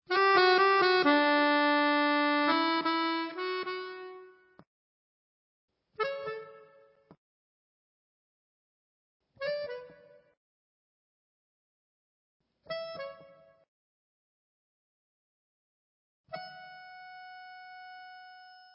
LIVE IN BRUSSELS